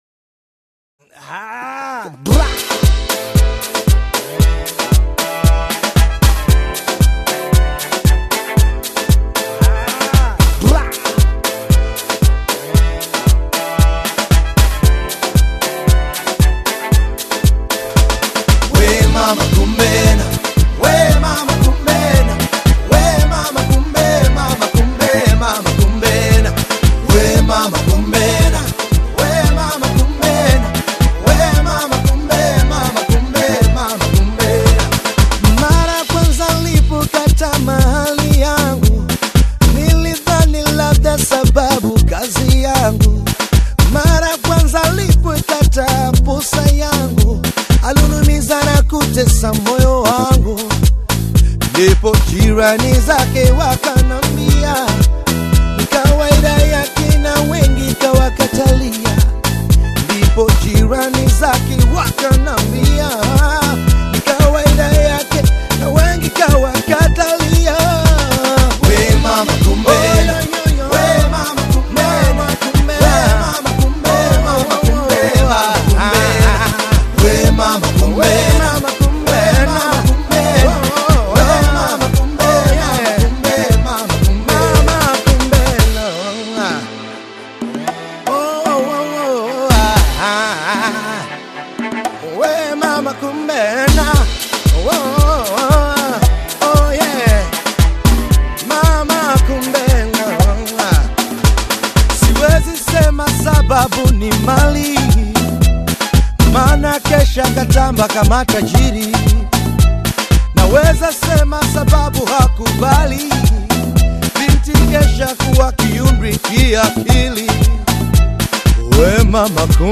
old-school Bongo Fleva hit
rich vocals and smooth delivery
The melody combines R&B, Afro-soul, and Swahili rhythm